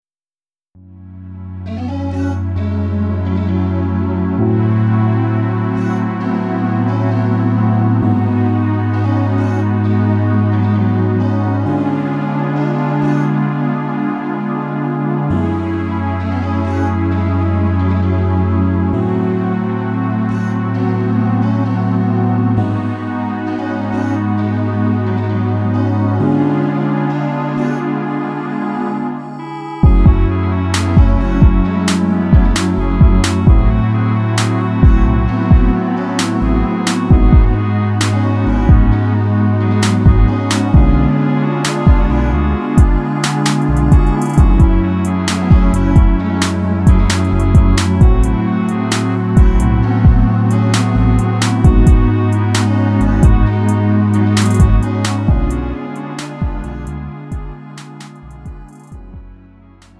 음정 -1키 3:56
장르 구분 Lite MR